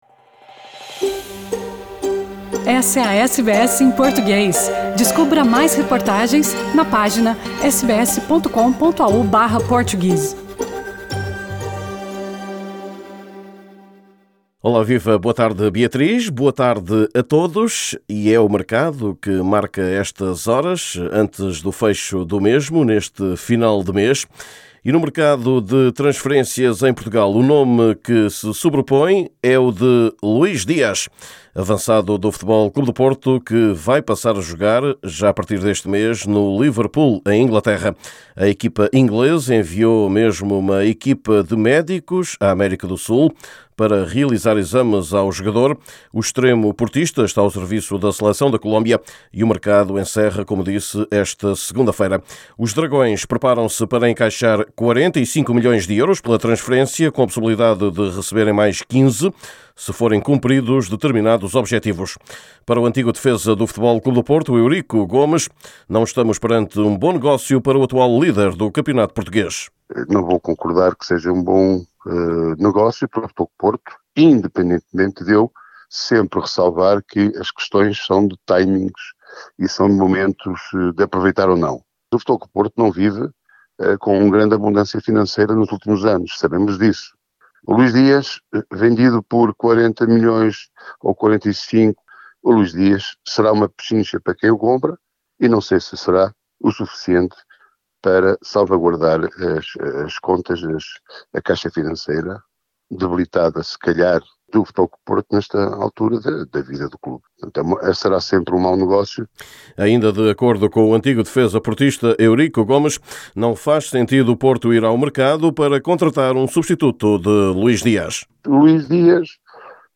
Neste boletim semanal, lugar ainda a uma renovação com um treinador português “lá fora”. Por fim, falamos da presença lusa nos próximos Jogos Olímpicos de Inverno, na China.